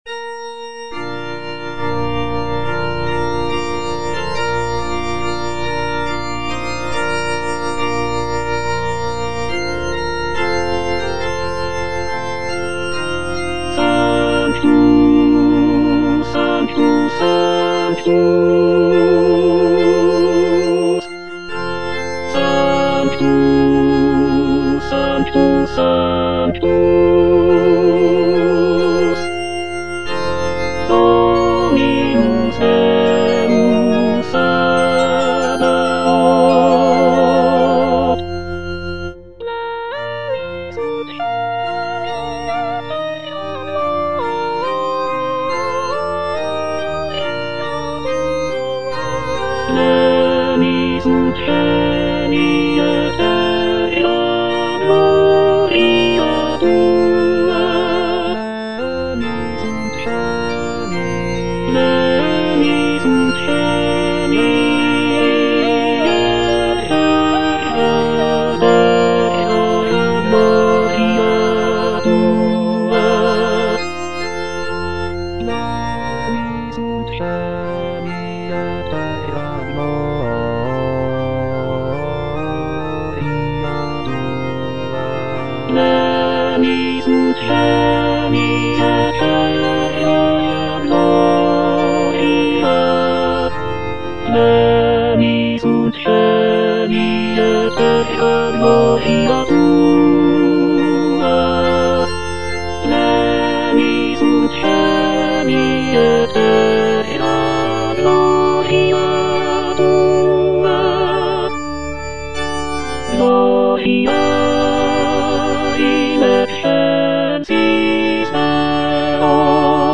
Tenor (Emphasised voice and other voices) Ads stop
is a sacred choral work rooted in his Christian faith.